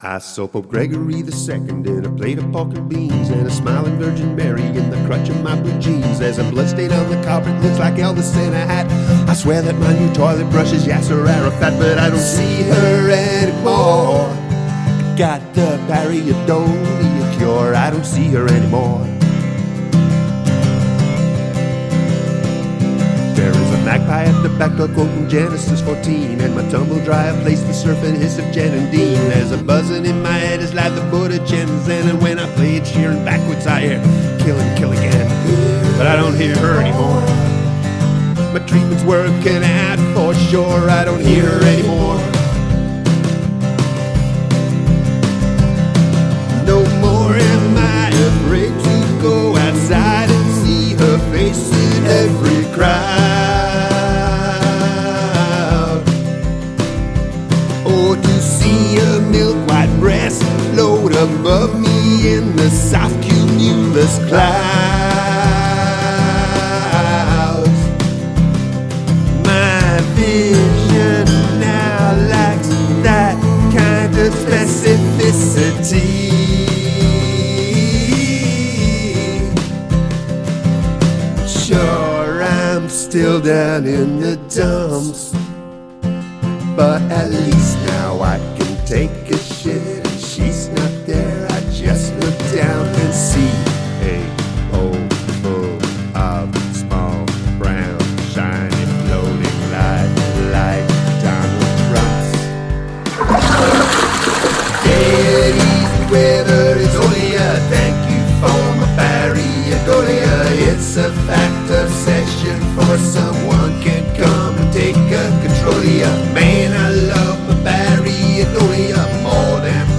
Use of field recording
I like how you incorporated the toilet sound.